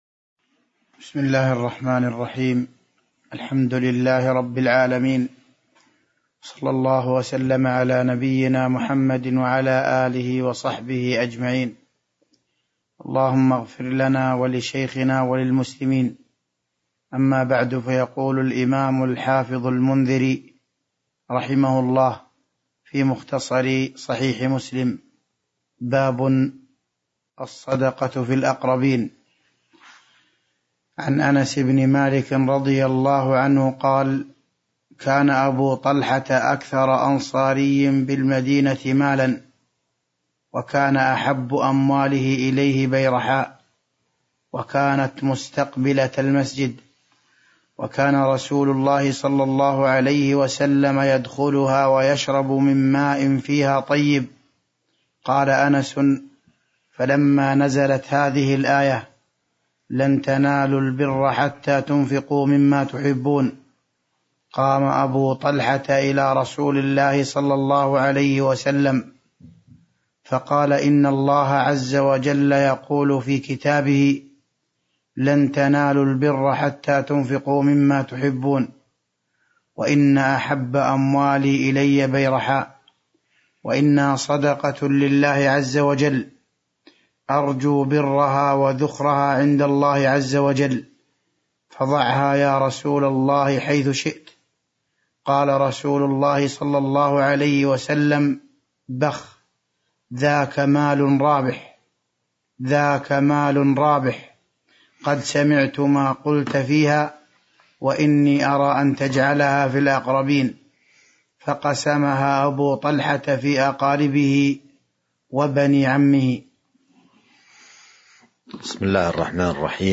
تاريخ النشر ١٨ رجب ١٤٤٢ هـ المكان: المسجد النبوي الشيخ